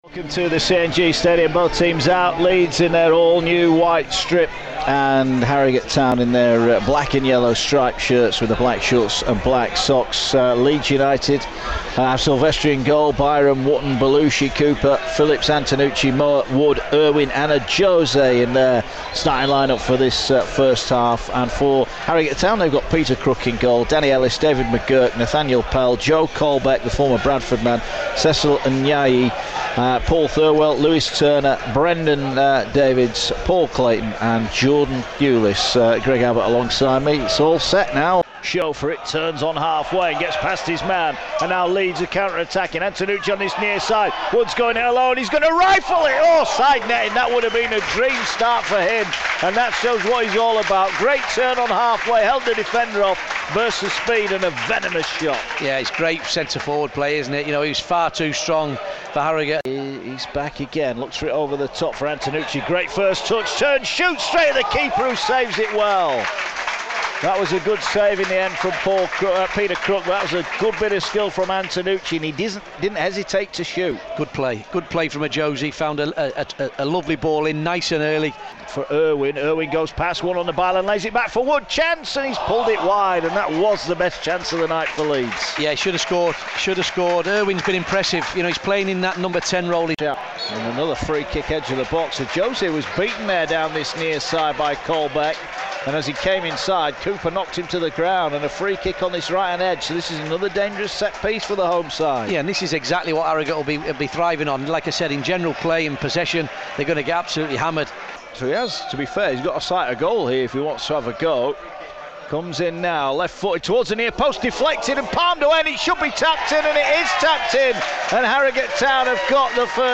Highlights of Leeds United's first pre-season game at Harrogate on Radio Yorkshire.